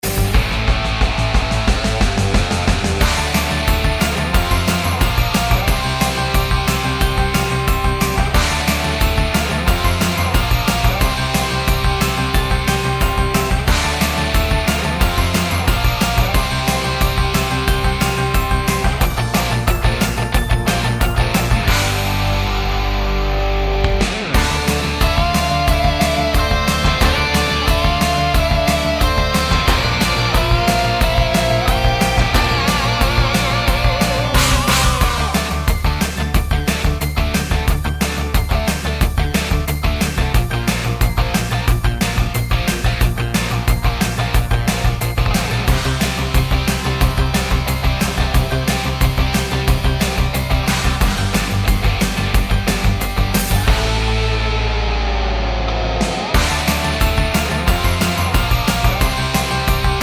For Solo Singer Rock 4:14 Buy £1.50